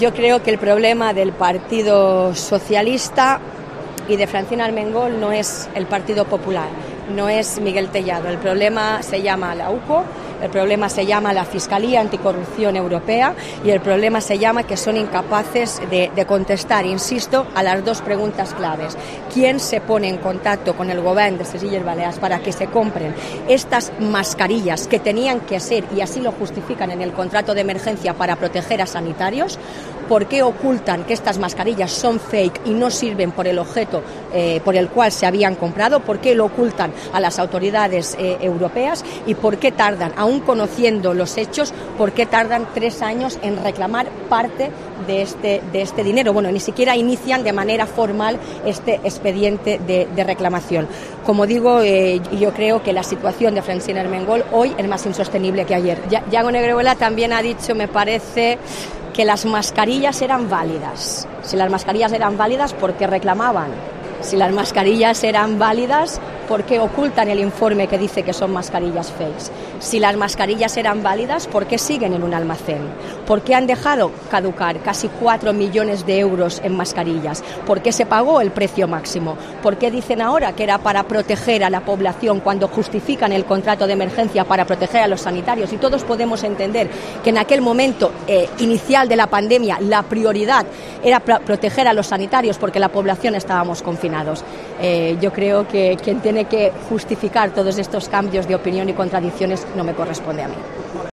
Marga Prohens, presidenta del Govern de les Illes Balears
En declaraciones a los medios en la feria ITB de Berlín, la líder del Ejecutivo autonómico ha criticado que en su comparecencia, Armengol siga sin responder a las que, a su juicio, son dos de las cuestiones fundamentales, con quién contacta el anterior Govern para la adquisición de las mascarillas y por qué la reclamación se hace tres años después.